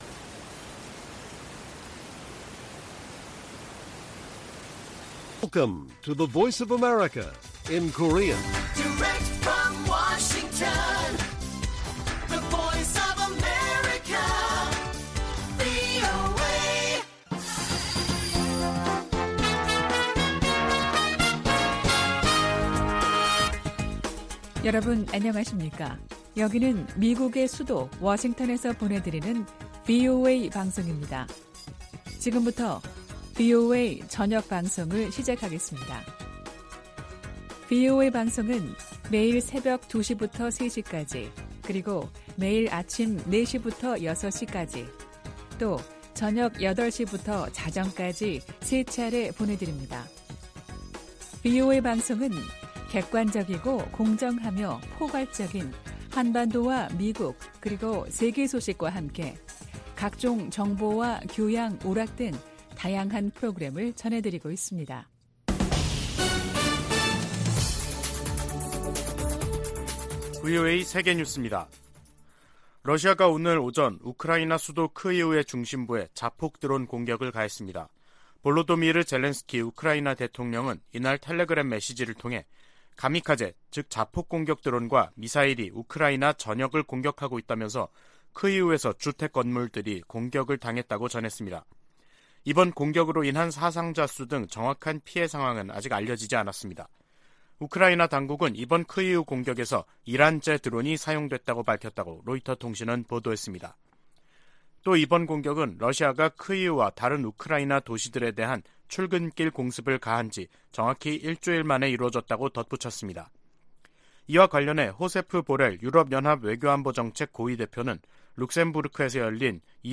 VOA 한국어 간판 뉴스 프로그램 '뉴스 투데이', 2022년 10월 17일 1부 방송입니다. 북한이 연이어 9.19 남북 군사합의를 노골적으로 위반하는 포 사격에 나서면서 의도적으로 긴장을 고조시키고 있습니다. 미 국무부는 북한에 모든 도발을 중단할 것을 촉구하면서 비핵화를 위한 외교와 대화에 여전히 열려 있다는 입장을 재확인했습니다. 유엔은 북한의 안보리 결의 위반을 지적하며 대화 재개를 촉구했습니다.